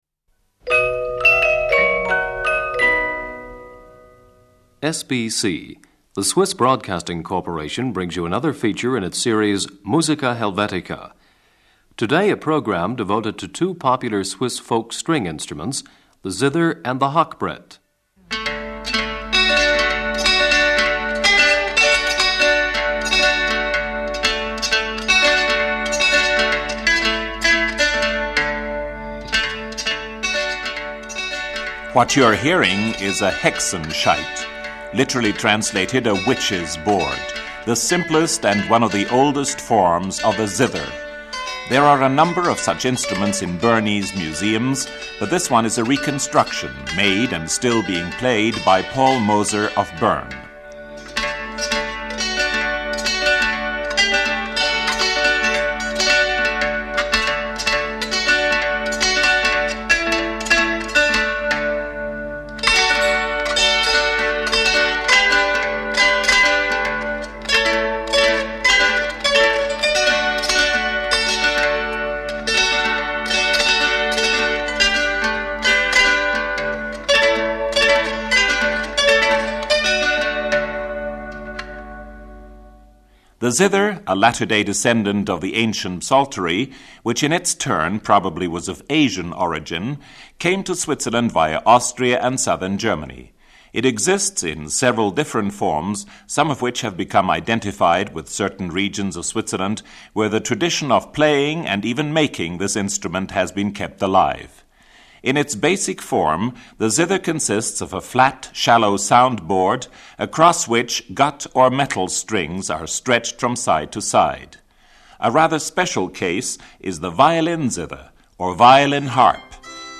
Swiss Folk Instruments. Stringed Instruments (Zither and Hackbrett).
violin harp.
With mixed choir.
hackbrett.
“Bänziger String Group” (Herisau, Canton Appenzell-Ausserrhoden), 2 violins, hackbrett, cello and bass.